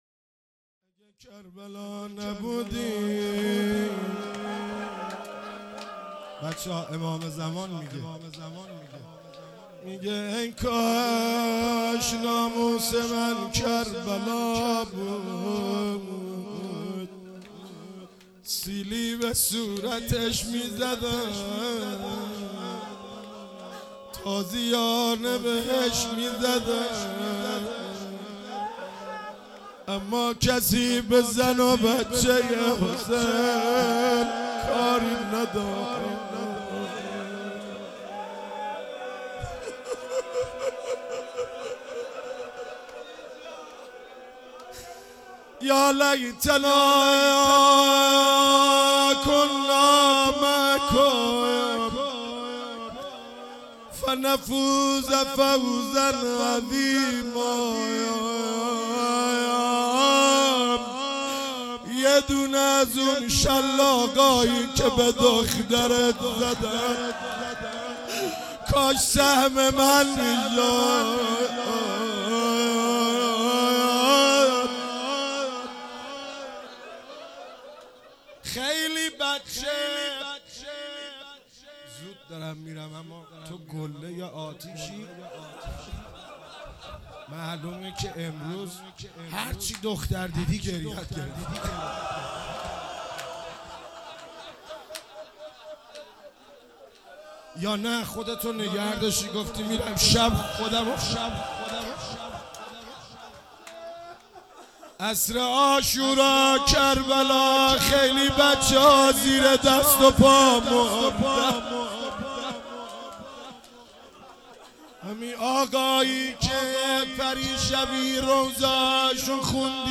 شب سوم محرم 96 - روضه - ای کاش ناموس من کربلا بود